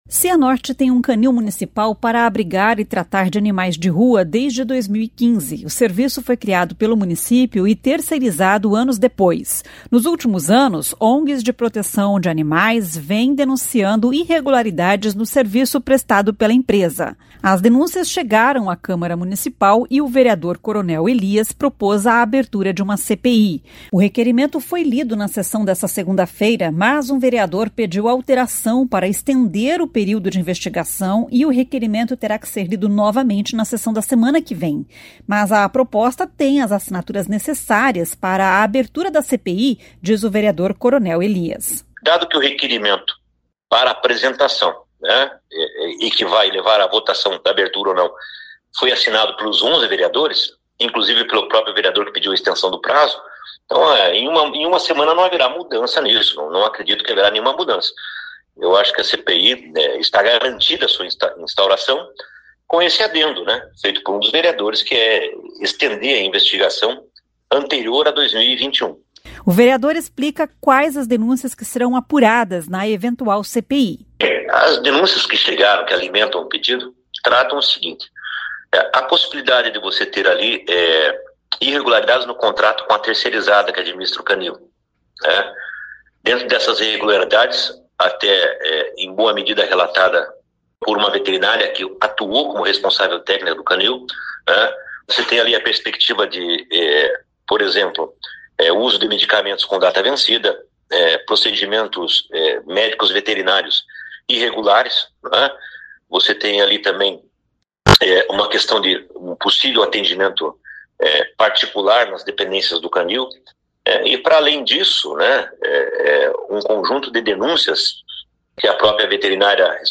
O vereador explica quais as denúncias que serão apuradas na eventual CPI.